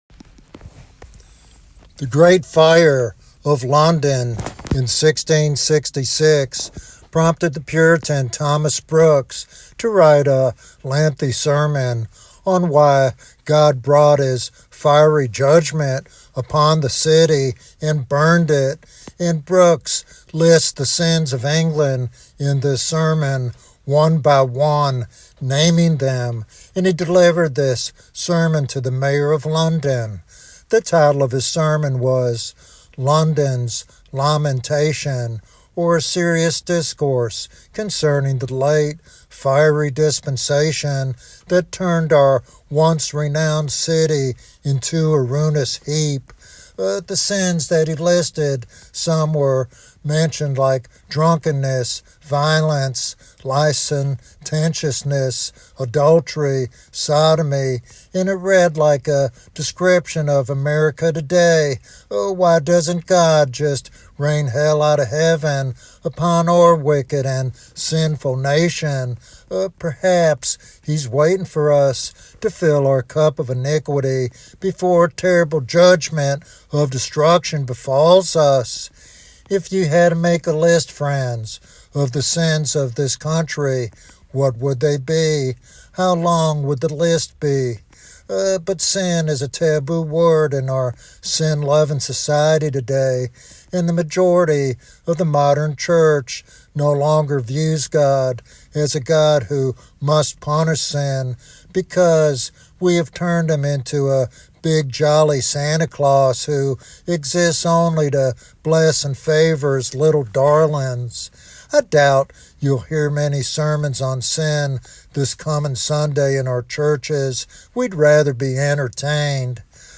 Sermon Series Sin